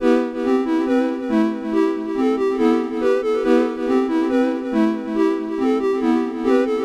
卡西欧延时长笛
描述：卡西欧长笛，增加延迟
标签： 140 bpm Trap Loops Flute Loops 1.15 MB wav Key : E FL Studio
声道立体声